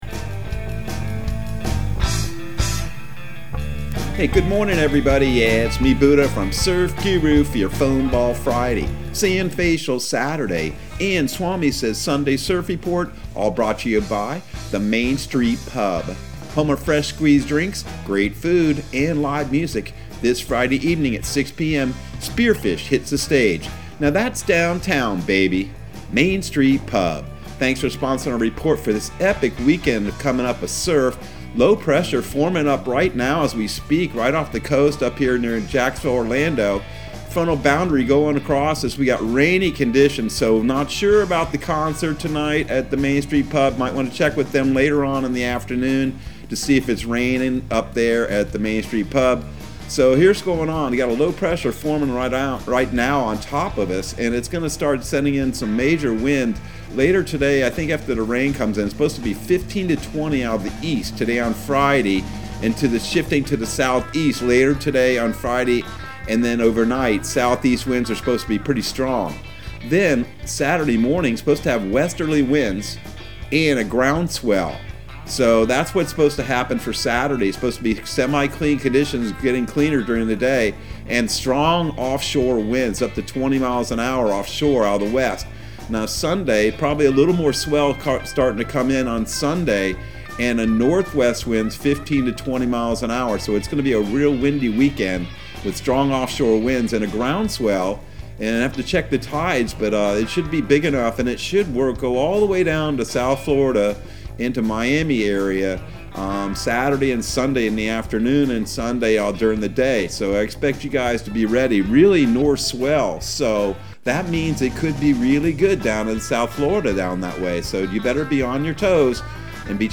Surf Guru Surf Report and Forecast 11/05/2021 Audio surf report and surf forecast on November 05 for Central Florida and the Southeast.